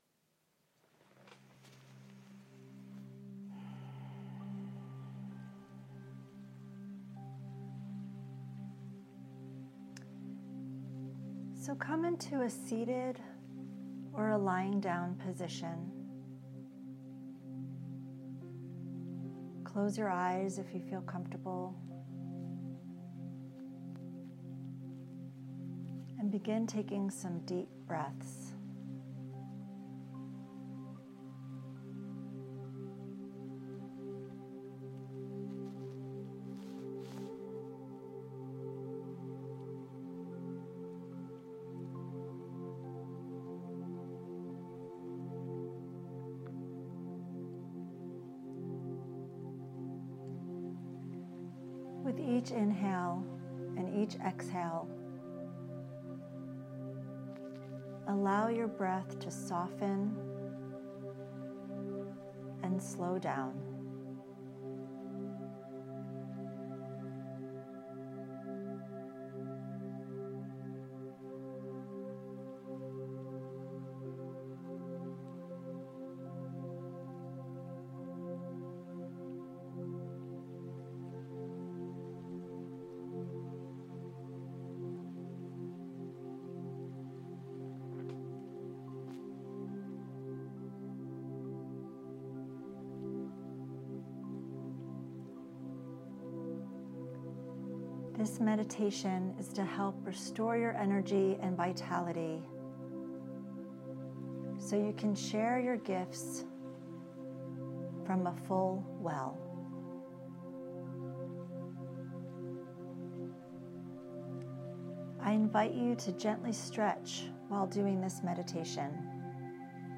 flow_meditation_2.mp3